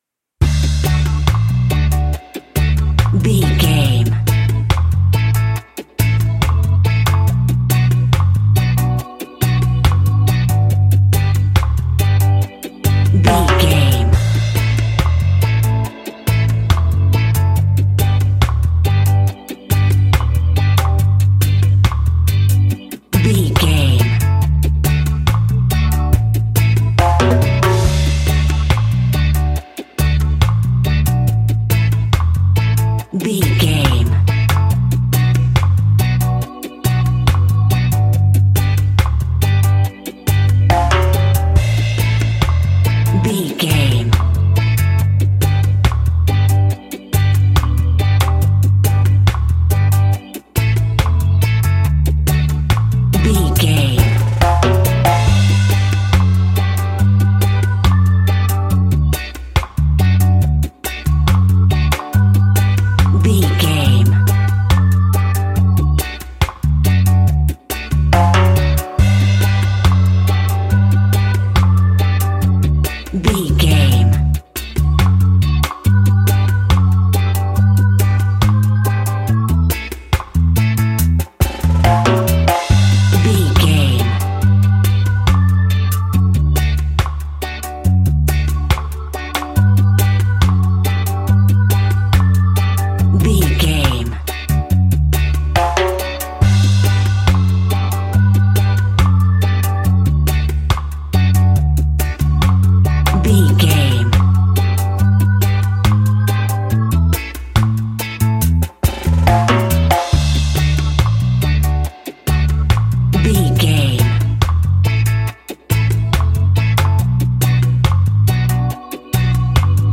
Classic reggae music with that skank bounce reggae feeling.
Ionian/Major
Slow
dub
reggae instrumentals
laid back
chilled
off beat
drums
skank guitar
hammond organ
percussion
horns